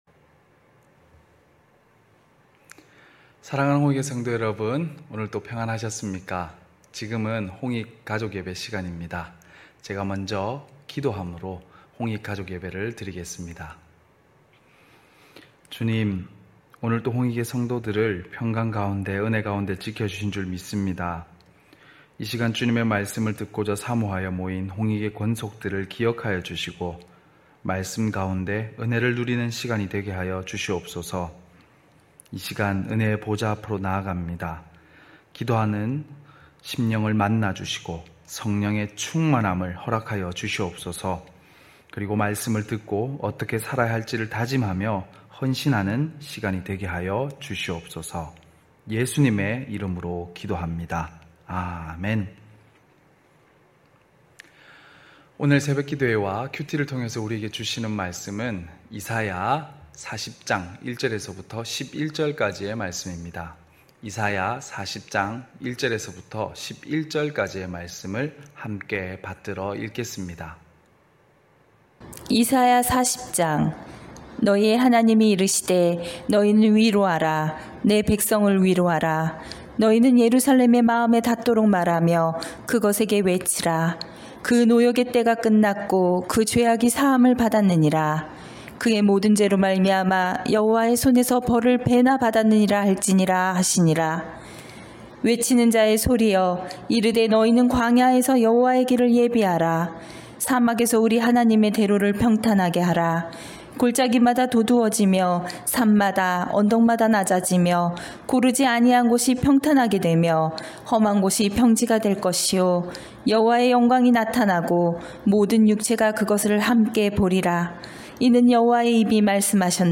9시홍익가족예배(7월12일).mp3